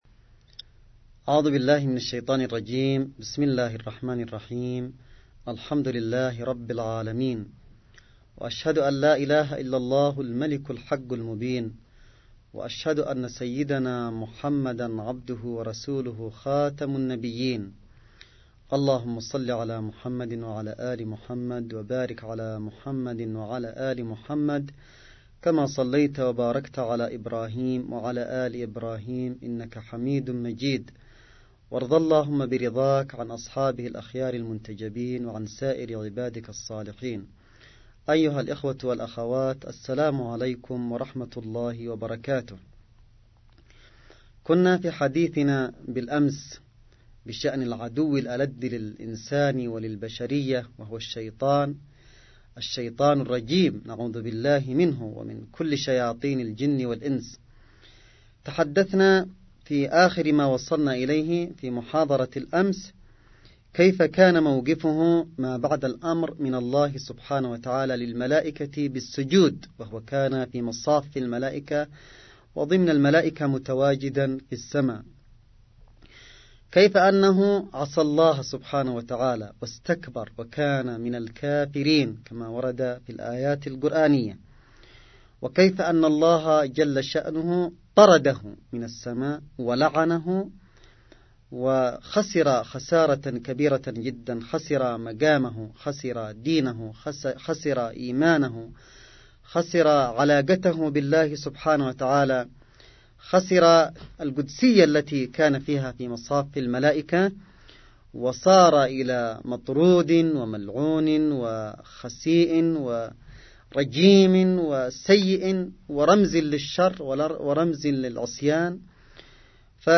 محاضرة_السيد_عبدالملك_بدر_الدين4.mp3